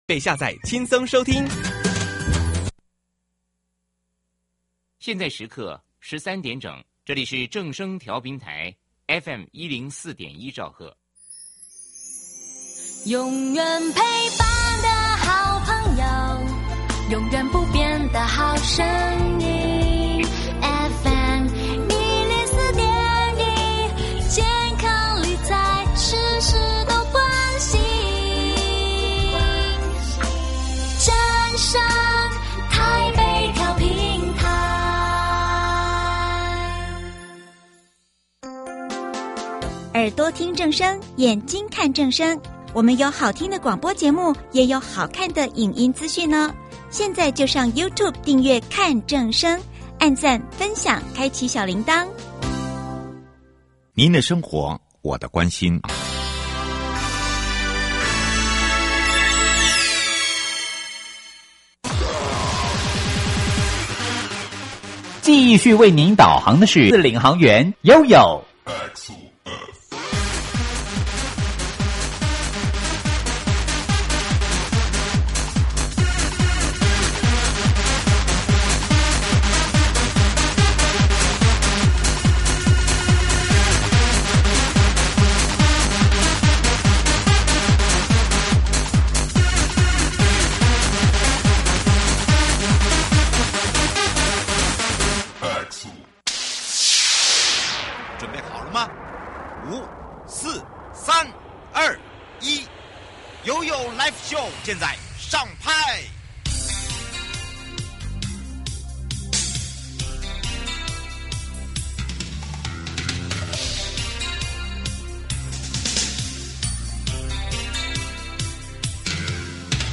受訪者： 營建你我他 快樂平安行~七嘴八舌講清楚~樂活街道自在同行!